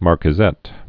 (märkĭ-zĕt, -kwĭ-)